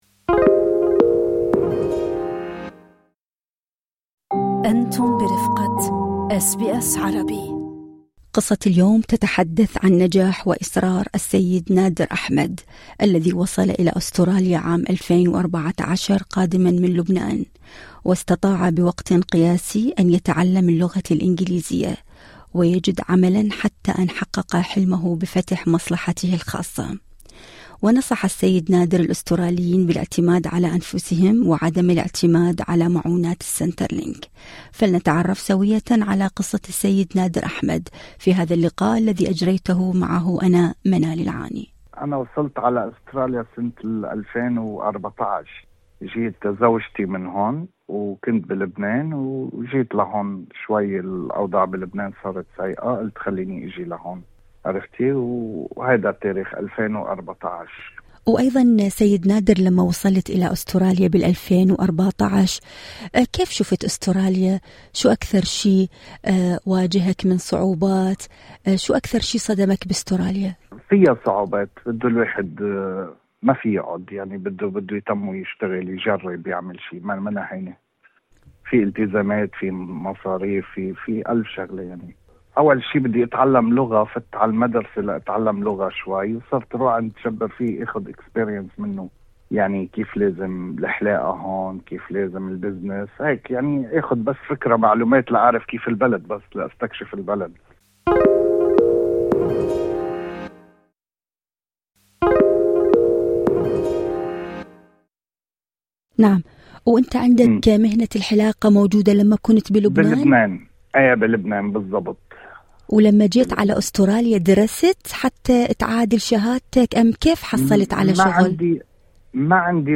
التفاصيل في المقابلة الصوتية اعلاه استمعوا لبرنامج "أستراليا اليوم" من الاثنين إلى الجمعة من الساعة الثالثة بعد الظهر إلى السادسة مساءً بتوقيت الساحل الشرقي لأستراليا عبر الراديو الرقمي وتطبيق Radio SBS المتاح مجاناً على أبل وأندرويد.